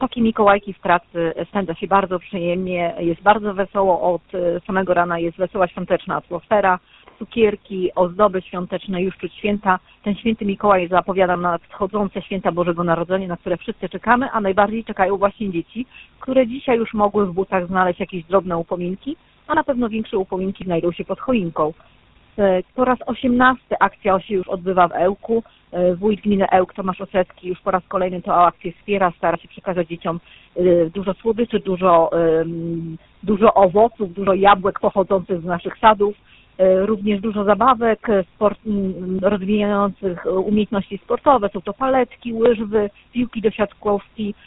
Dzieciaki z Mikołajkowego Autobusu przyjęła zastępca wójta Magdalena Fuk
zastępca-wójta-Magdalena-Fuk-Elk.mp3